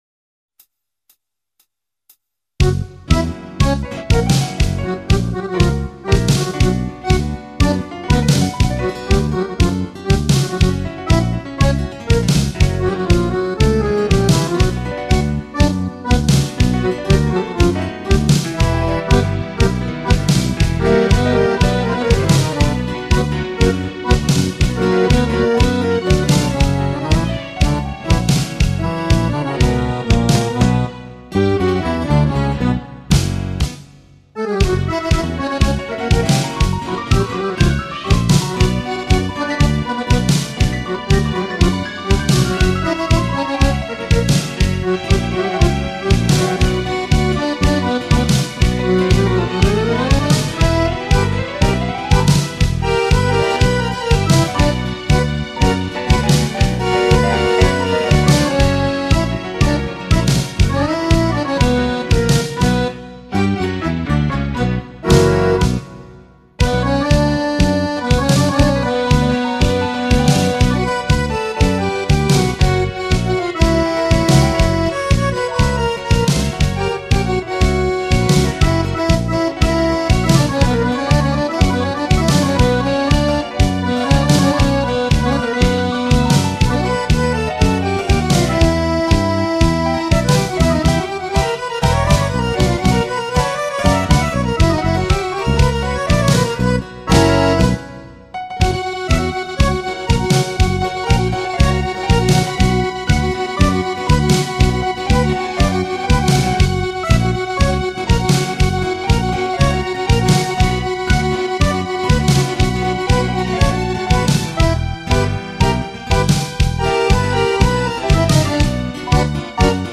Танцы разных стилей 0 626 Добавлено в плейлист